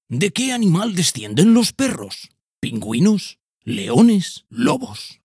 TEST HISTORIA PERRO-Narrador-06.ogg